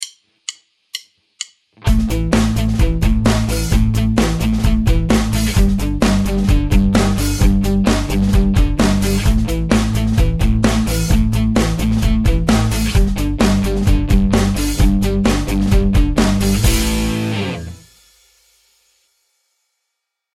Mängime medikaga kõik noodid alla, nii kõlavad need ühtlaselt ja rokipäraselt.